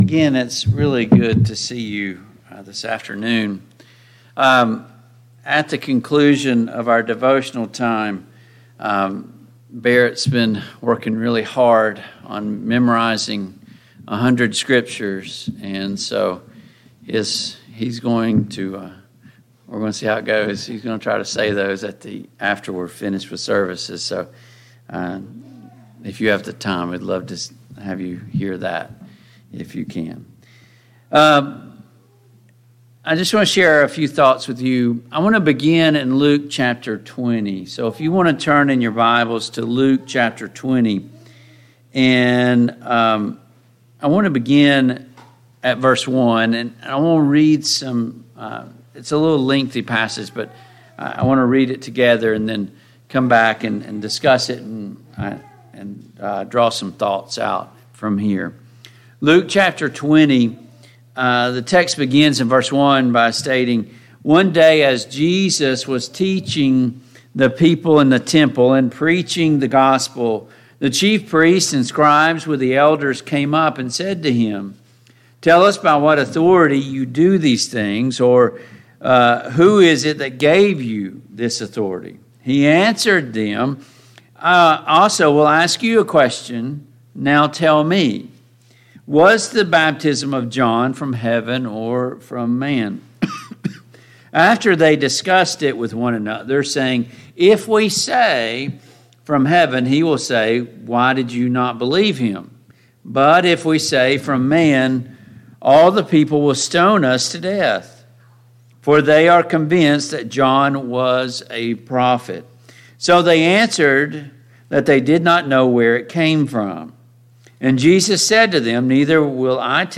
Luke 20:19-28 Service Type: PM Worship « 5.